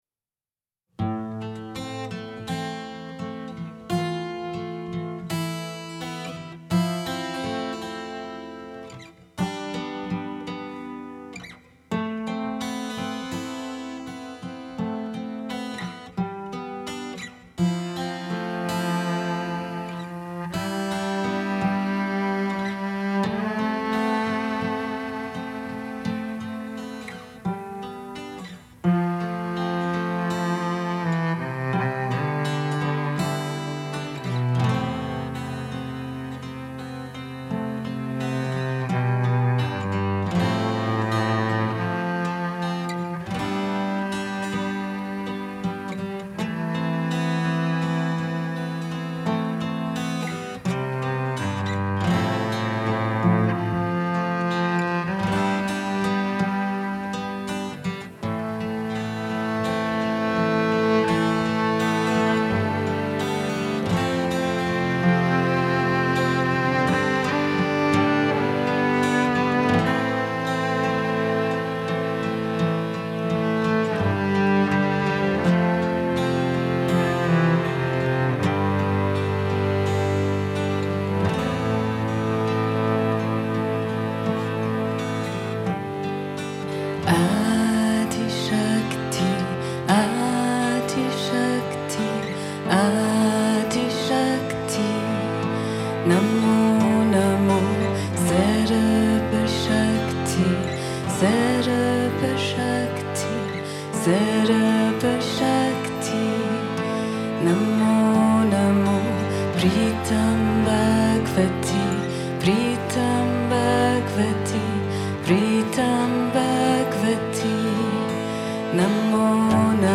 in Corfu, Summer 2019